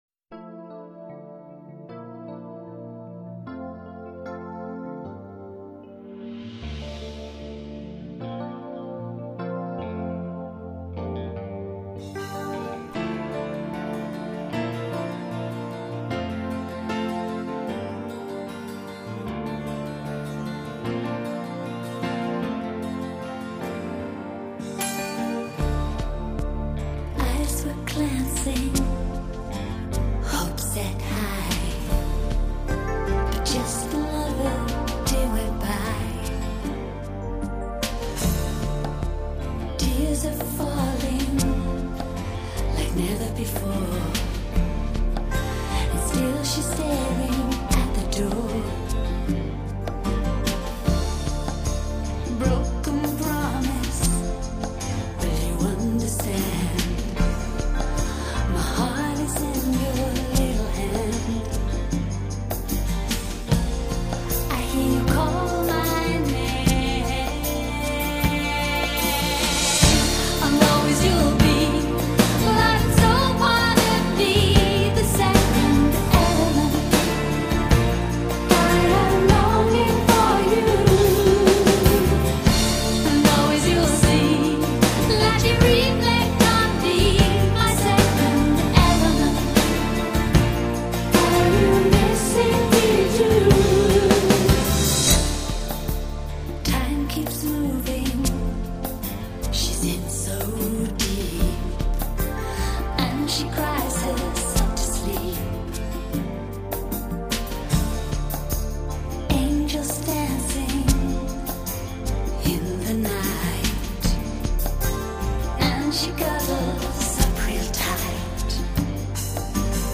音乐类型：古典音乐